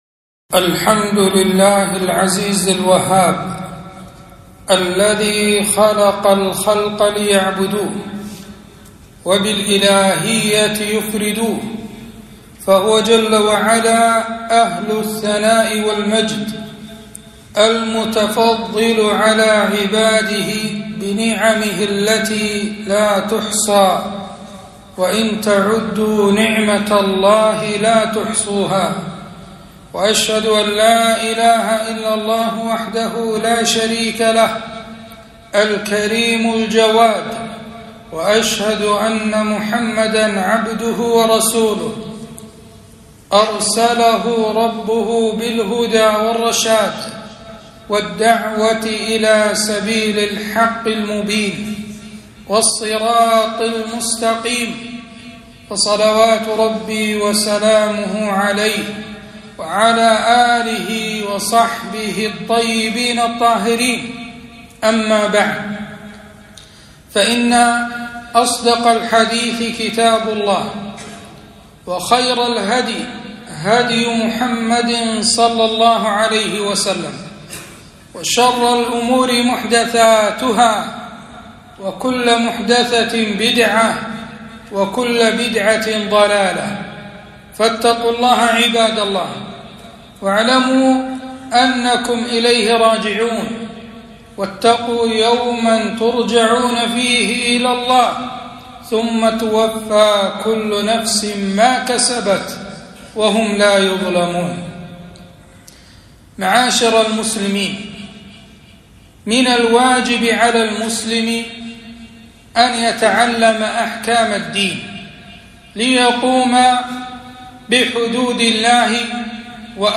خطبة - الربا وخطره